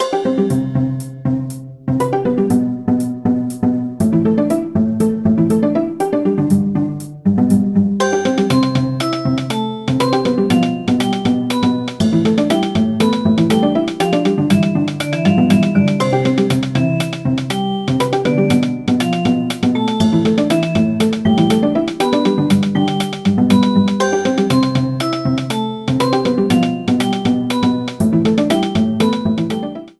Boss music